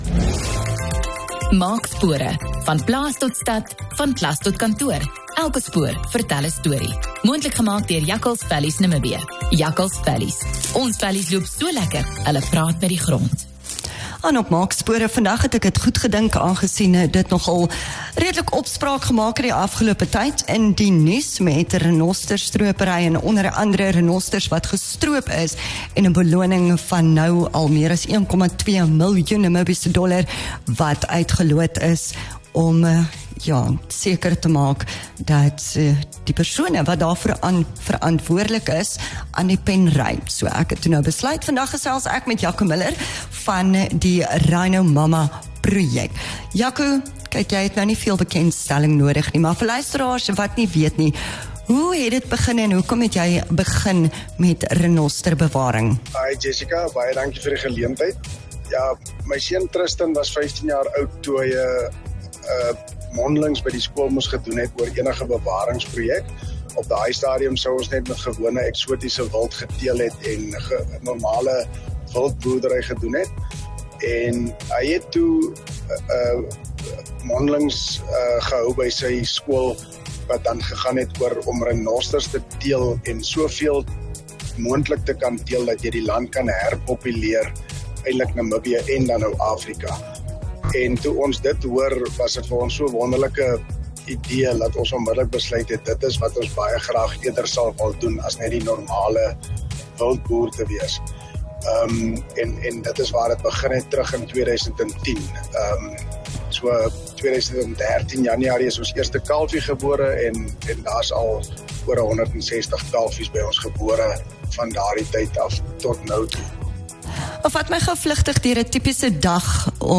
Kosmos 94.1 Gesprekke